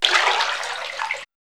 3005L SPLASH.wav